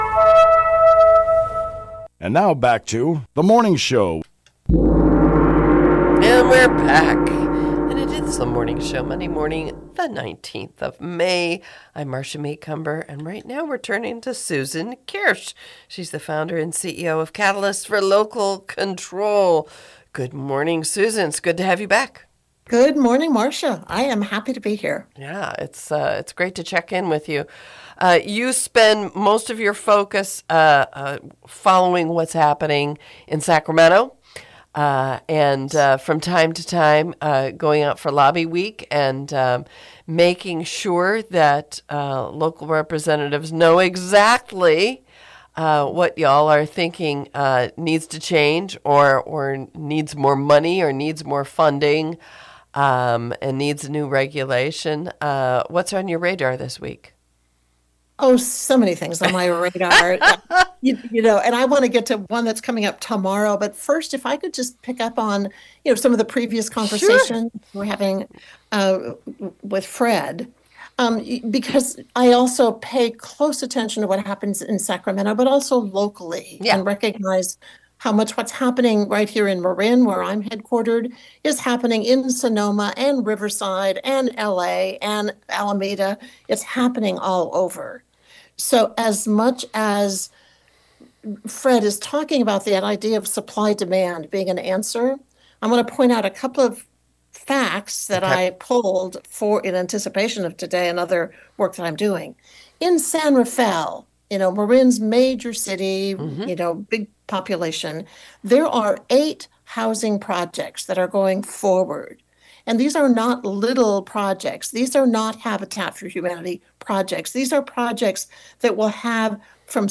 Here is the interview for April 21, 2025